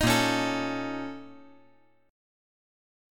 A#mM11 Chord
Listen to A#mM11 strummed